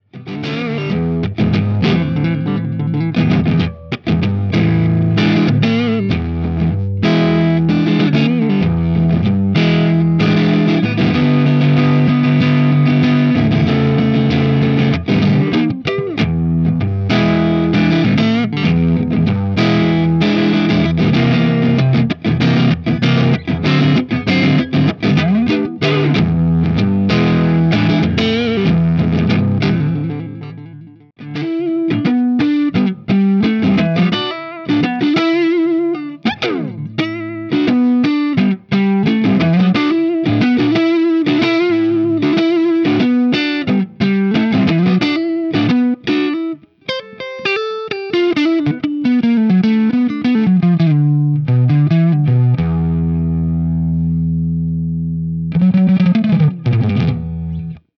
Totally unstructured, free form clips!
With the JTM I used my TTA Trinity cab, the Marshall with Greenbacks, and the Mesa Recto with V-30s.
I set up 2 mics this time – a Shure SM57 and an Audix i5. The i5 has a more pronounced top end and bigger bottom and compliments the 57’s midrange thing really well.
D_JTM3_Jumpered_Strat_Neck_Greens.mp3